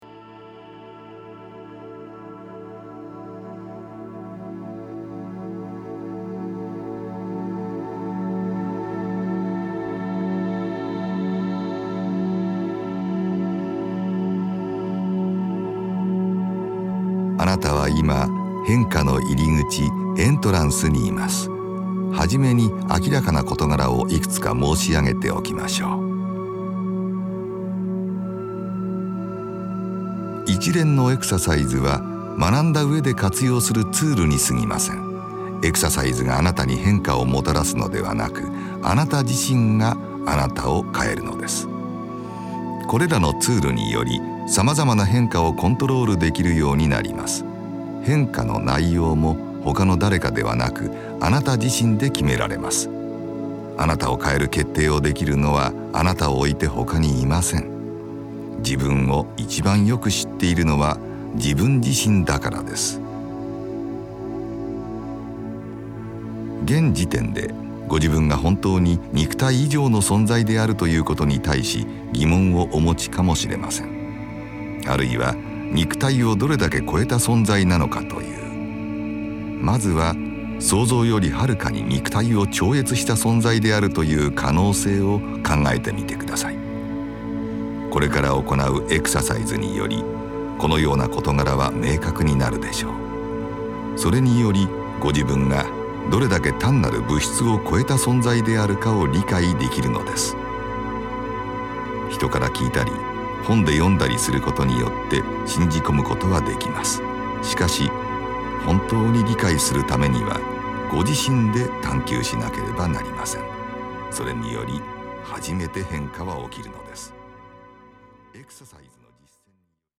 このオーディオ・ガイダンスのプロセスは、何層もの複雑なオーディオ信号を組み合わせることによって共鳴現象を起こし、特殊な脳波を発生させて、意識を特別な状態へと誘導するものです。
ヘミシンク信号に加えて、ミュージックや音声ガイダンス、あるいはかすかな音響効果などが組み合わされて、その効果はさらに高められます。